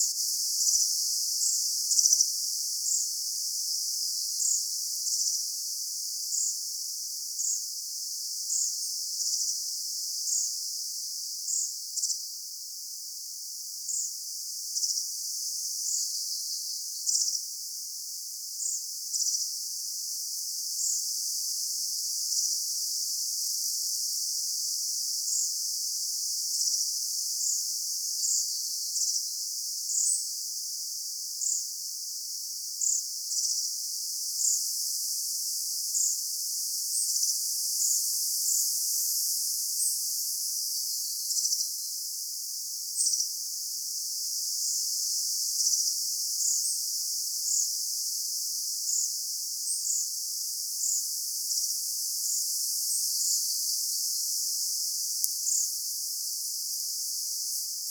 oikealla puolella pesii kuusitiainen.
kuusitiainen päästeli pesän vierellä erikoisia ääniä, kirjosiepon poikasia?
tuollaisia_erikoisia_aania_ilmeisesti_tuo_kuvien_kuusitiainen_paasteli_pesan_vierella.mp3